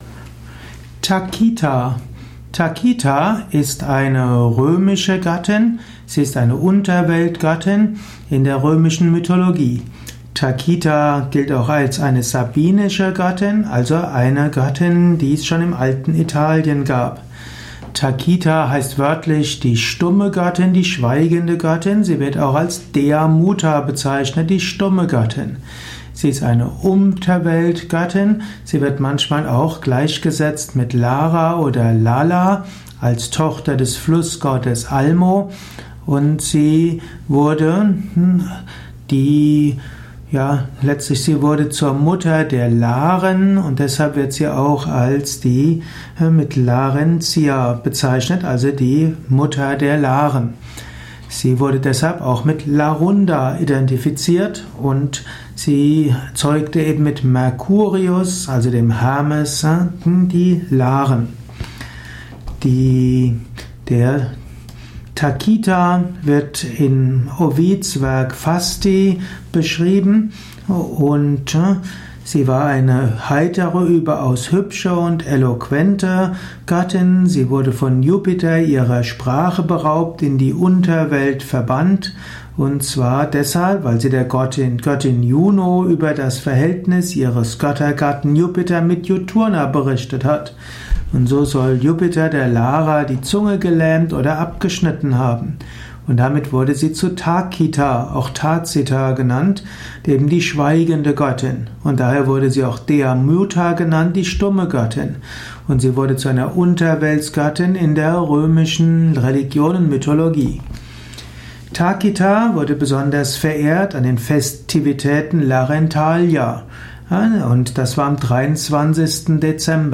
Dies ist die Tonspur eines Videos, zu finden im Yoga Wiki.